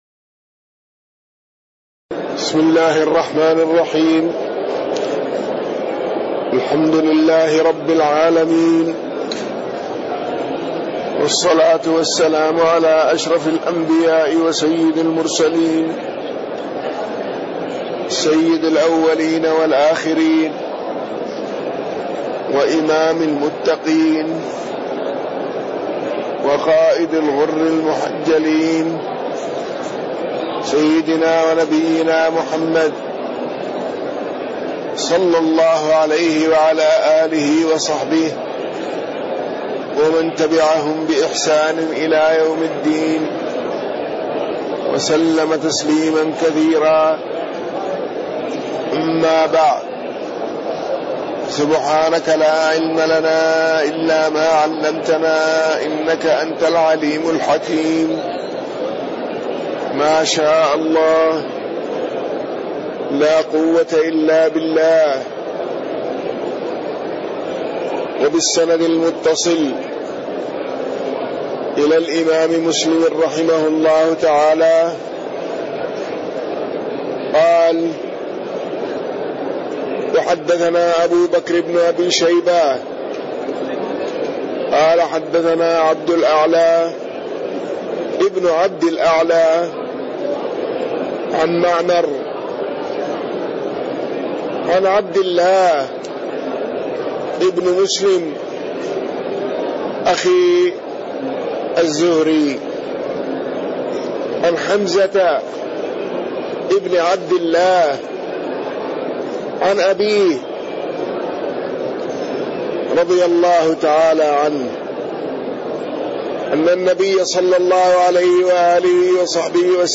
تاريخ النشر ١٥ رمضان ١٤٣٢ هـ المكان: المسجد النبوي الشيخ